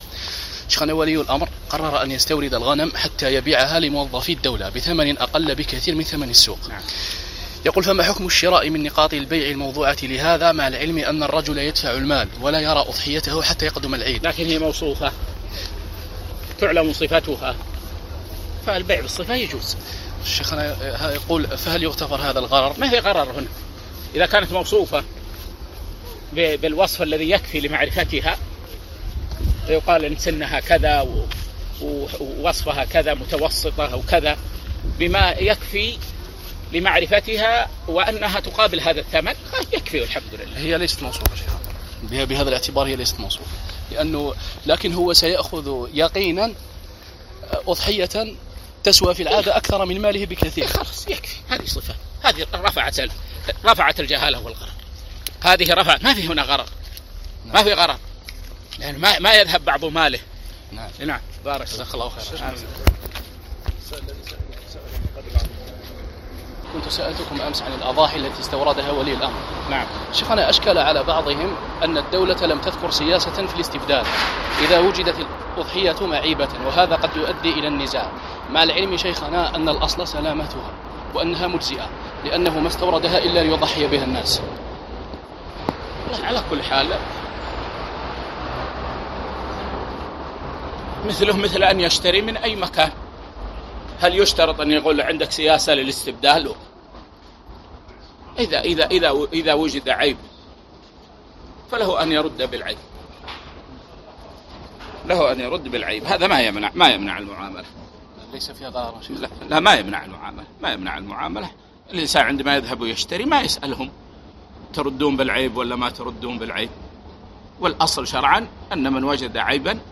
💥جـديـد_الفتاوى💥